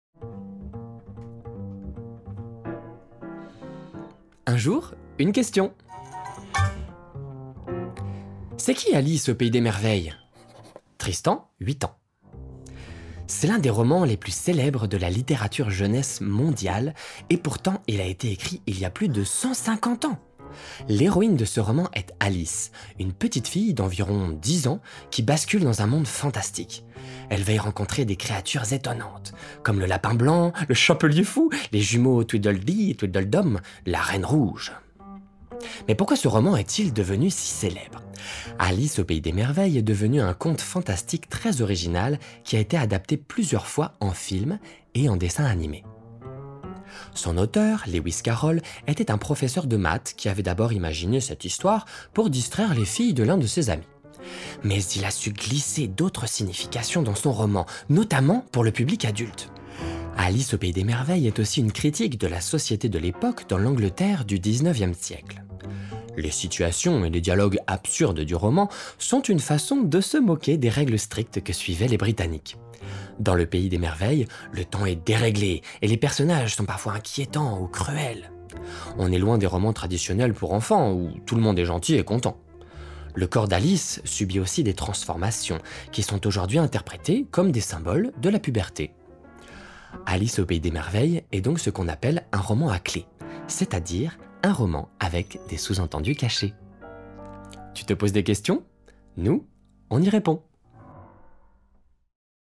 bande démo son pédagogie enfant
Voix off
24 - 42 ans - Baryton Ténor